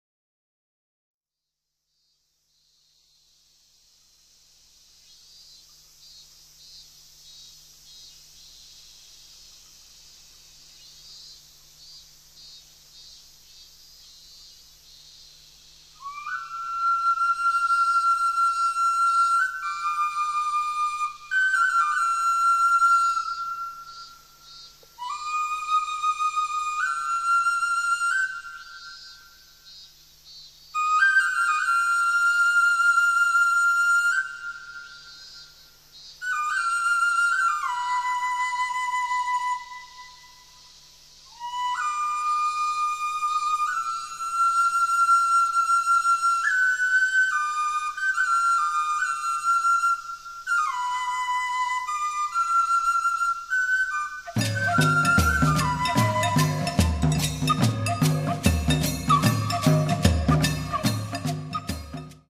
at studio Voice
稲取の蝉と中国笛との会話
横笛(自作陶器)
素焼の笛(メキシコ)
尺八運指笛(自作陶器)
タム
しめ太鼓
チェケレ(ブラジル)